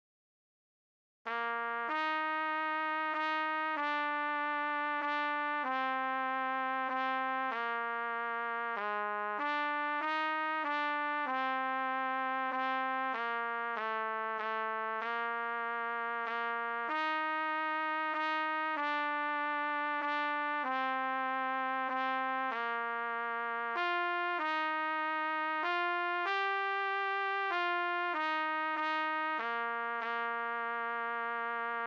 C=Harmony/Bass Part-for beginner players